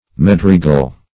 medregal - definition of medregal - synonyms, pronunciation, spelling from Free Dictionary
medregal - definition of medregal - synonyms, pronunciation, spelling from Free Dictionary Search Result for " medregal" : The Collaborative International Dictionary of English v.0.48: Medregal \Med"re*gal\, n. (Zool.) See Bonito , 3.